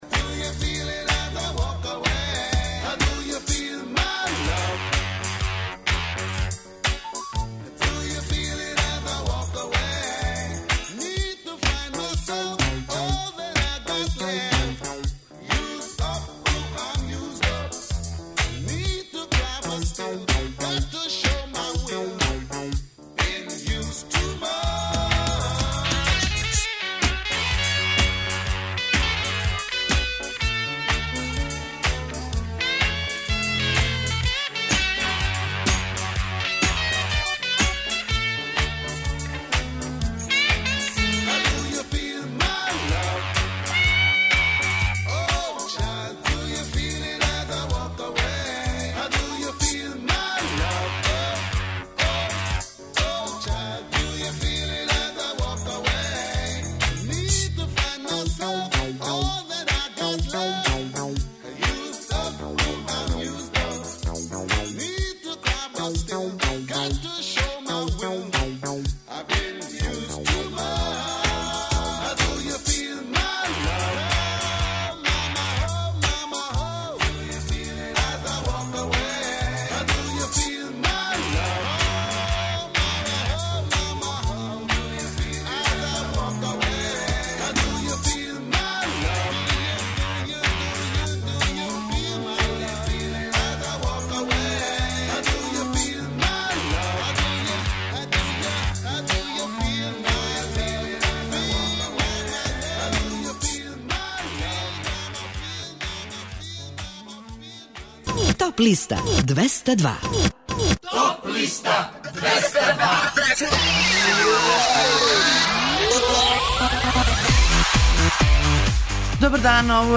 преузми : 26.83 MB Топ листа Autor: Београд 202 Емисија садржи више различитих жанровских подлиста.